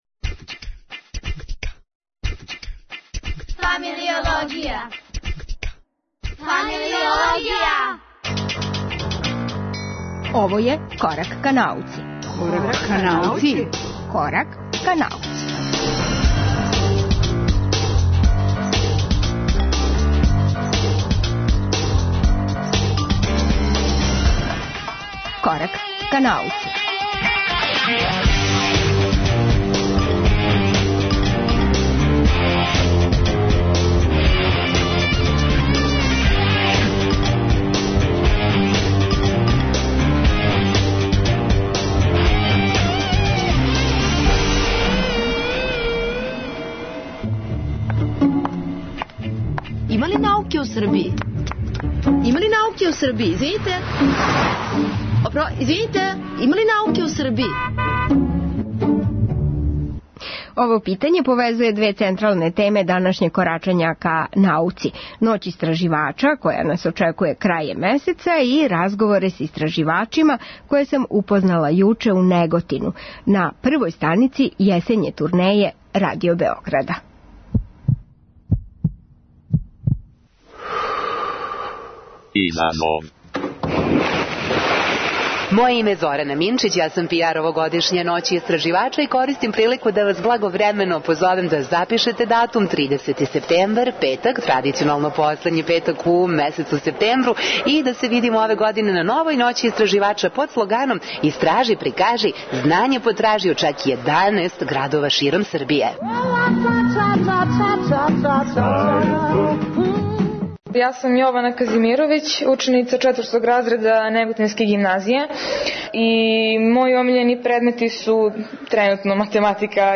Лајт-мотив овог издања емисије Корак ка науци јесте питање - има ли науке у Србији. Одговор смо потражили током турнеје Радио Београда у Неготину међу тамошњим гимназијлцима, историчатима науке и заљубљеницима у природу.